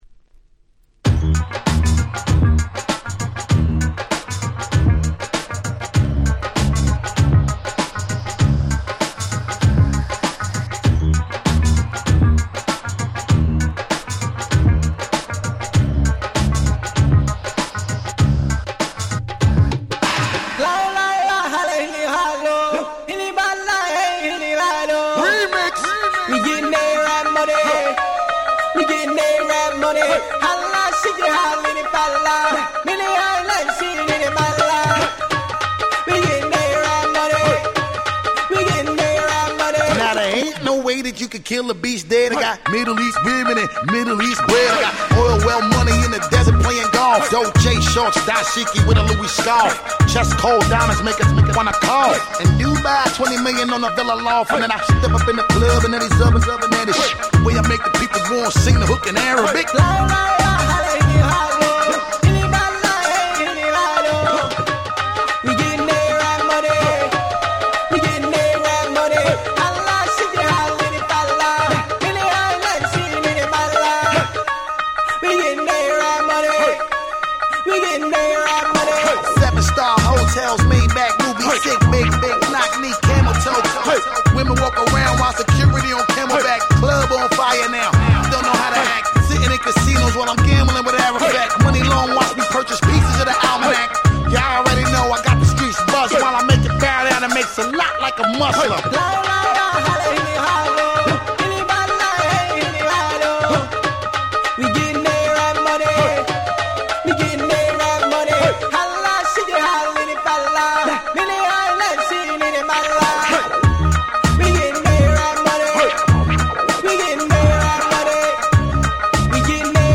08' Super Hit Hip Hop !!
(94-98 BPM)